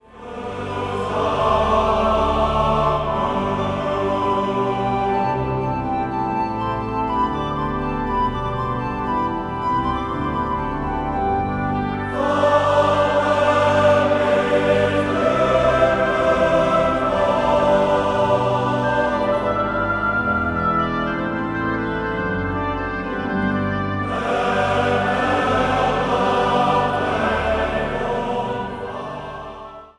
Zang | Mannenkoor